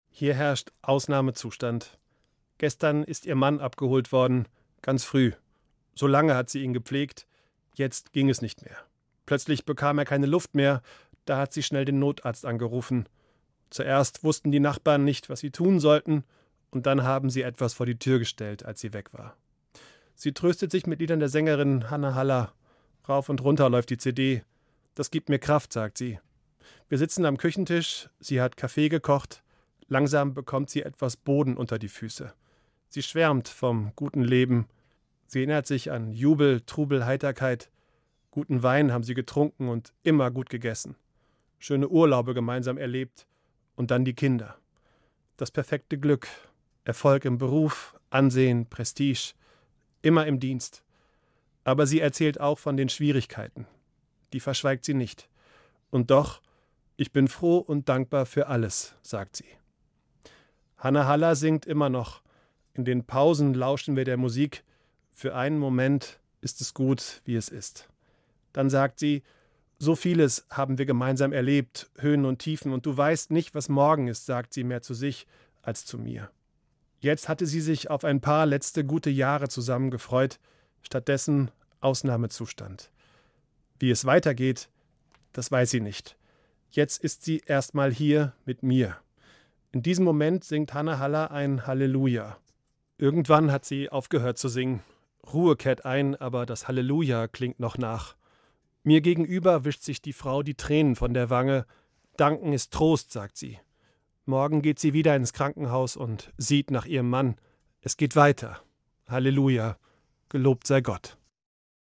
Evangelischer Pfarrer, Bad Hersfeld